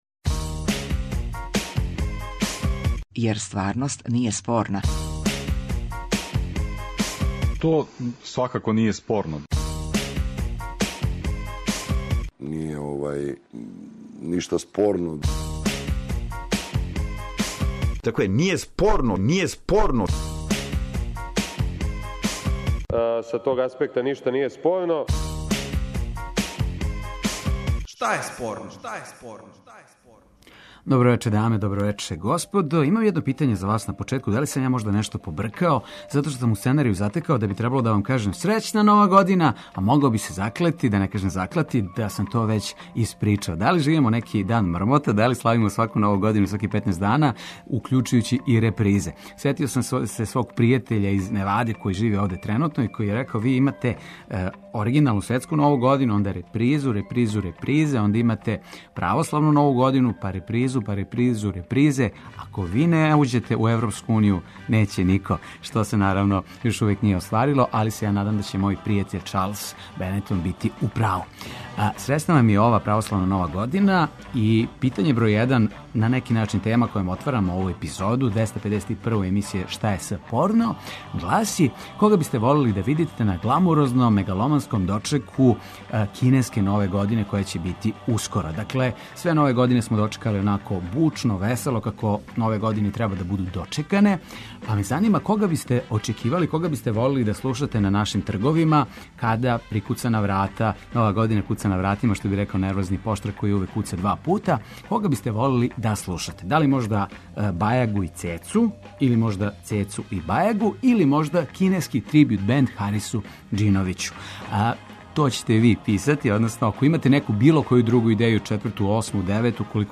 Радијски актуелно - забавни кабаре интерактивног карактера.